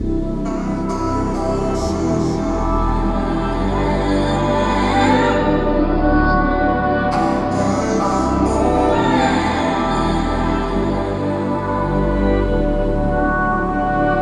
标签： 135 bpm Hip Hop Loops Pad Loops 2.39 MB wav Key : D
声道立体声